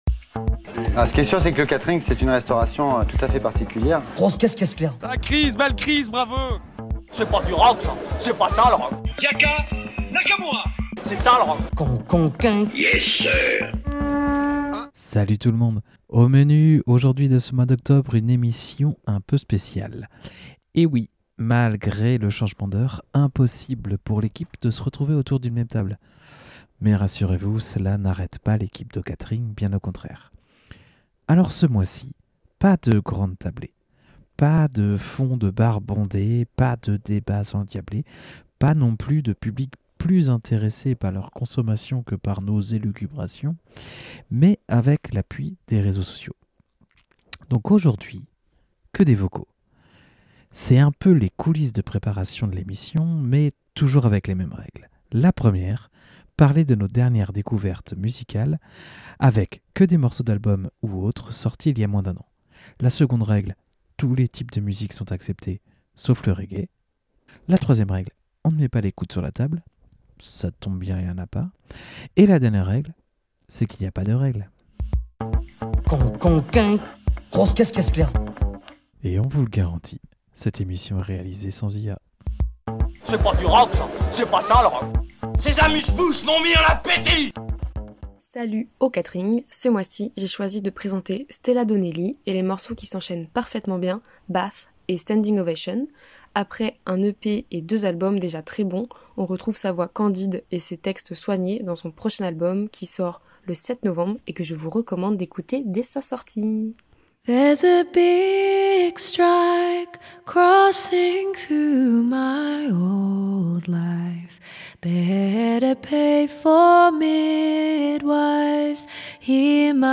Au menu de ce mois d’octobre, une émission un peu spéciale, malgré le changement d’heure, impossible pour l’équipe de se retrouver autour d’une même table !! Pas de grande tablée, pas de bruit de fond de bar bondé, pas de débats endiablés, pas de public plus intéressé par leur consommation que nos élucubrations, mais avec l’appui des réseaux sociaux.. des vocaux !!